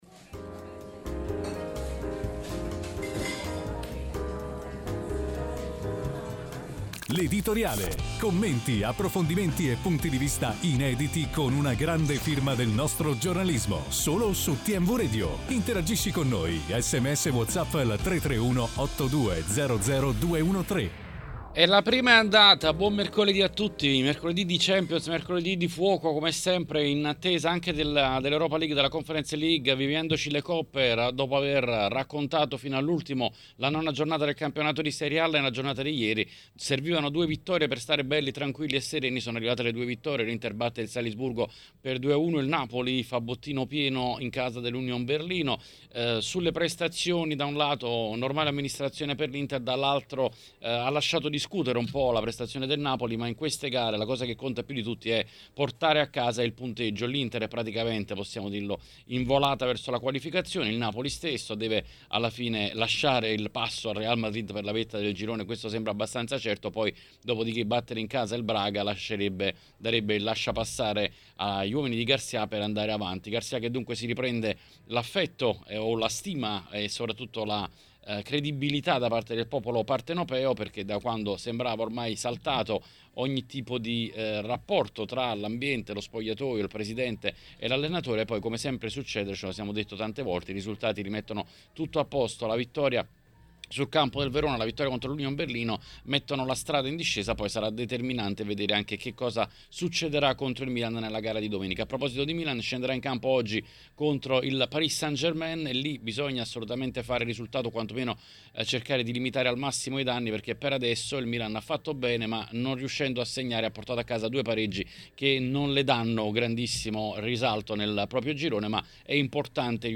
Durante l'appuntamento odierno con L’Editoriale è intervenuto sulle frequenze di TMW Radio Xavier Jacobelli